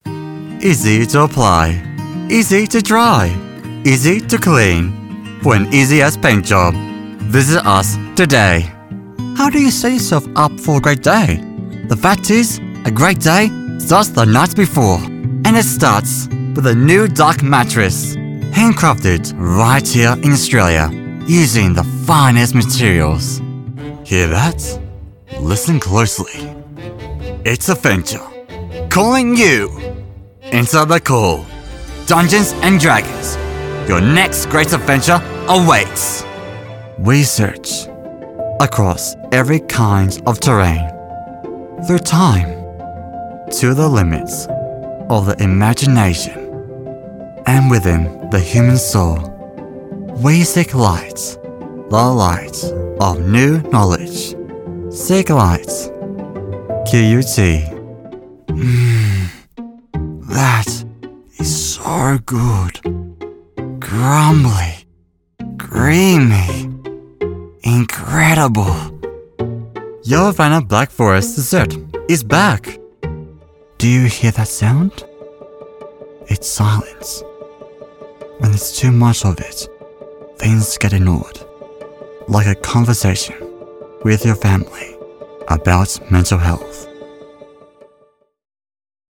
MALE VOICE OVER TALENT
0701_Commerical_Demo_Reel.mp3